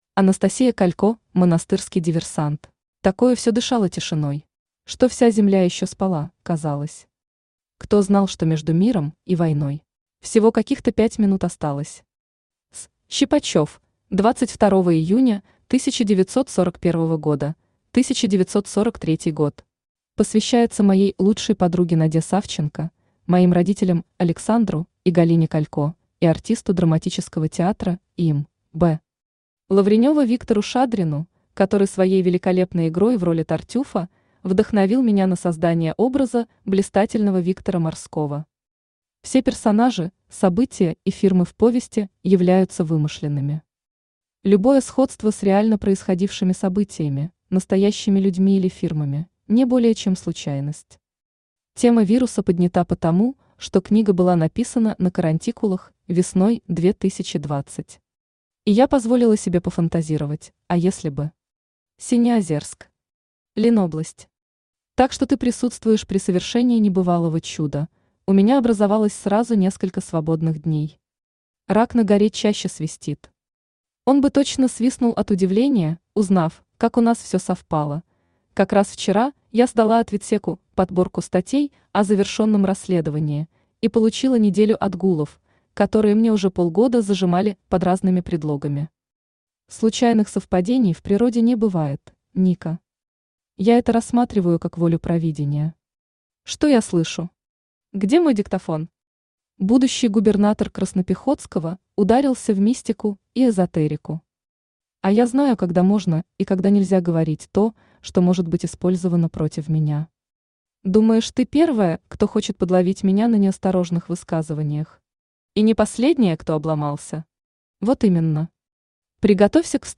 Aудиокнига Монастырский диверсант Автор Анастасия Александровна Калько Читает аудиокнигу Авточтец ЛитРес.